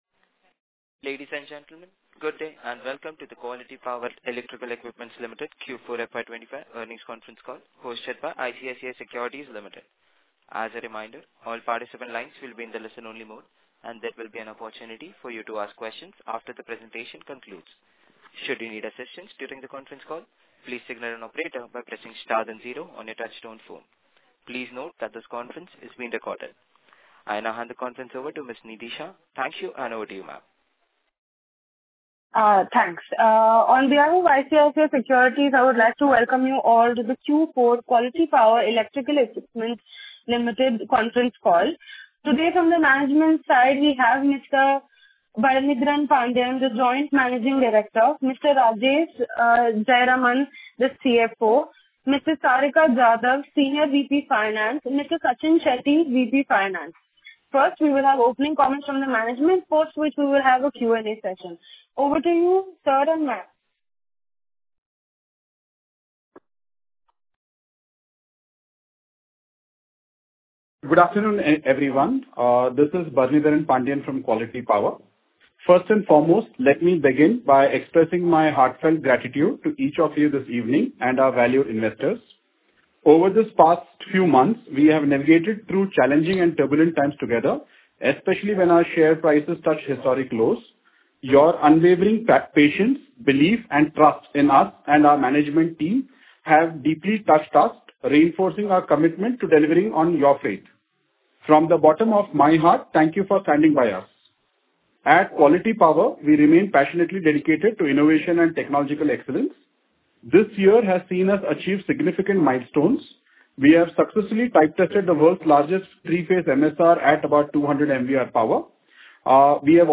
Concalls
Concall-Recording-Q4-and-FY2025.mp3